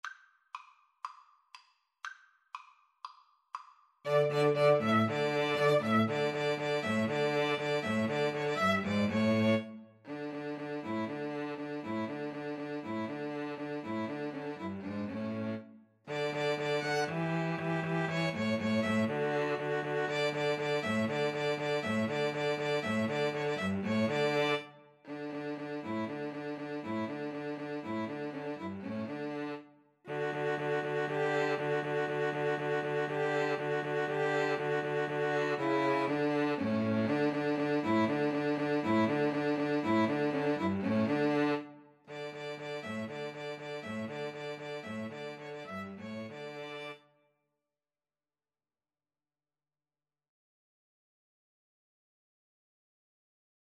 Play (or use space bar on your keyboard) Pause Music Playalong - Player 1 Accompaniment Playalong - Player 3 Accompaniment reset tempo print settings full screen
D major (Sounding Pitch) (View more D major Music for 2-Violins-Cello )
Allegro (View more music marked Allegro)
4/4 (View more 4/4 Music)
Classical (View more Classical 2-Violins-Cello Music)